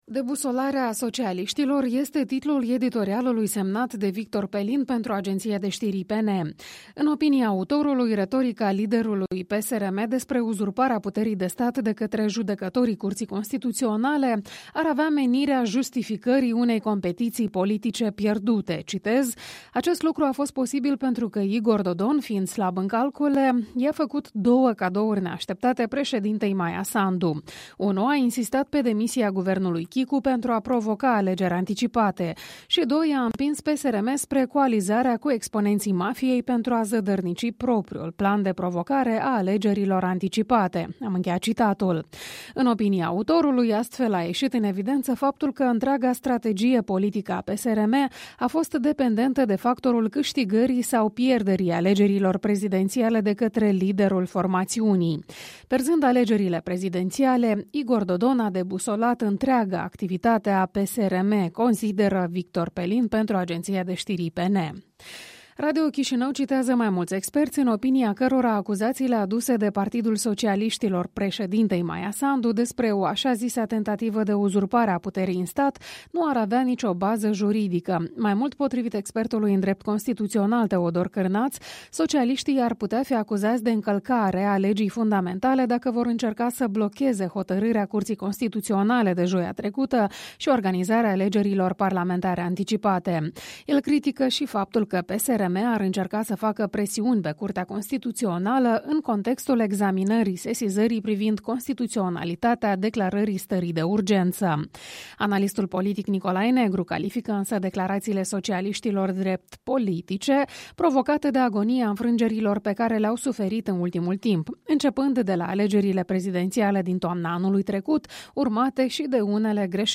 Revista matinală a presei la radio Europa Liberă.